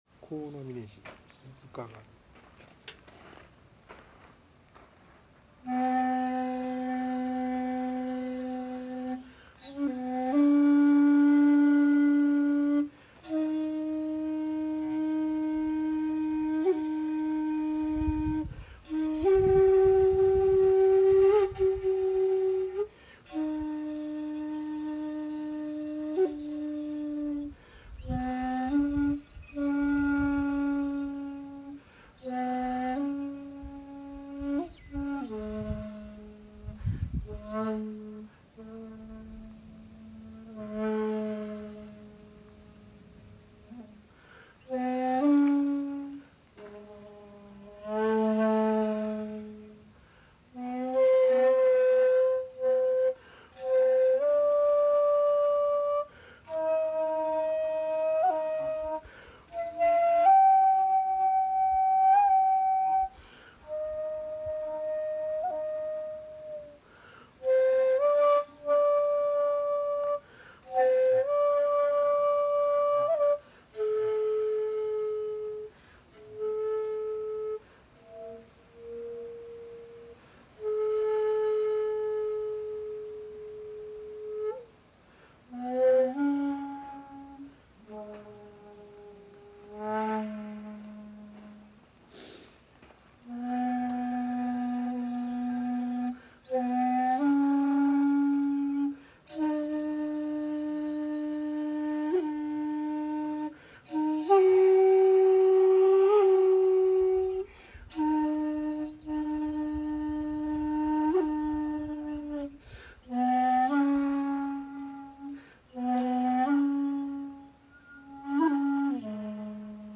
参拝を済ませてから尺八を吹奏しました。
◆◆（尺八音源：神峯寺にて「水鏡」）